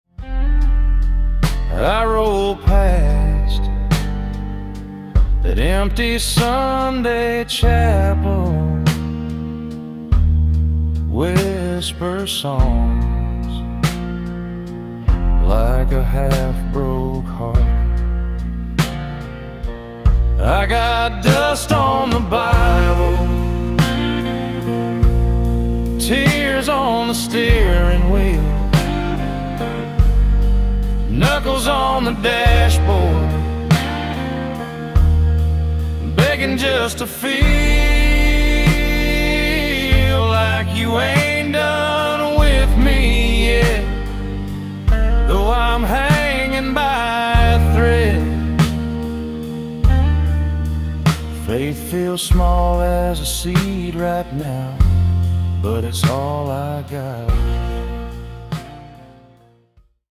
A.I.-produced country song